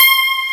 Index of /m8-backup/M8/Samples/Fairlight CMI/IIX/GUITARS